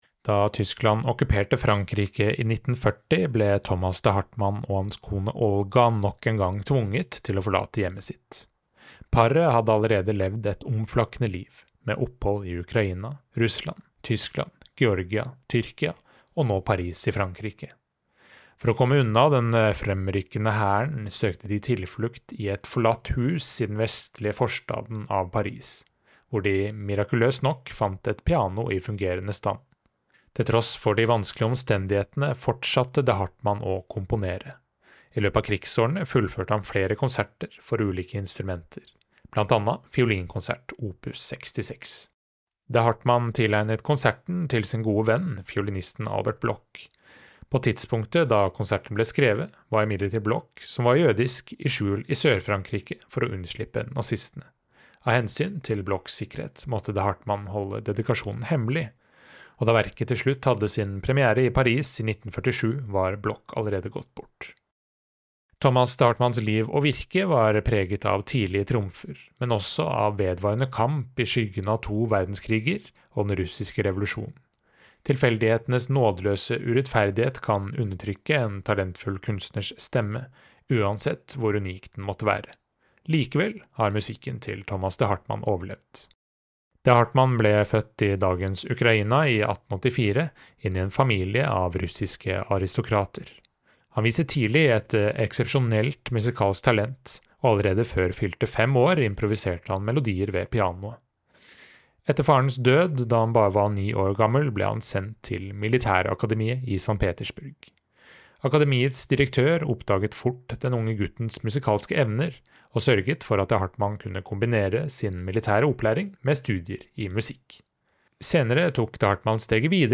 VERKOMTALE-Thomas-de-Harmanns-Fiolinkonsert.mp3